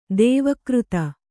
♪ dēva křta